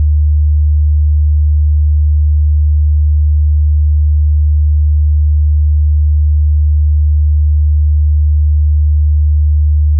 tone.wav